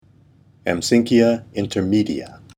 Pronunciation:
Am-sínck-i-a  in-ter-mè-di-a